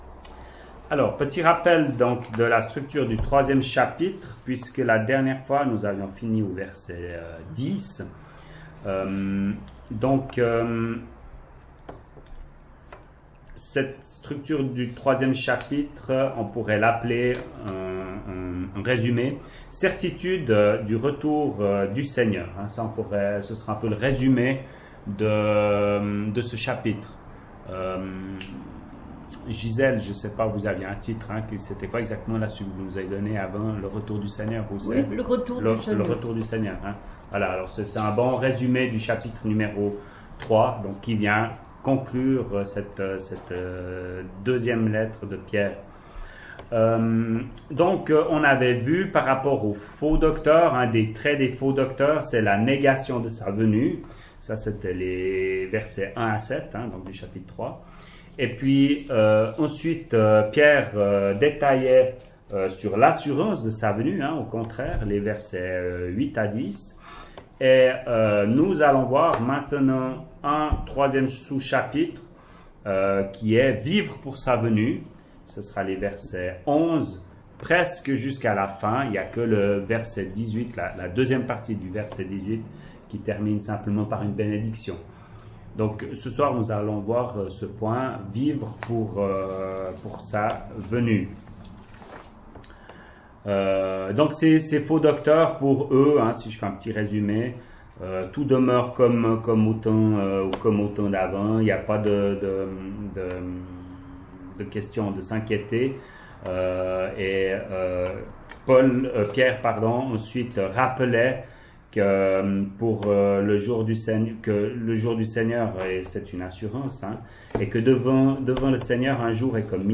ÉTUDE BIBLIQUE : Evole, le 31.07.2019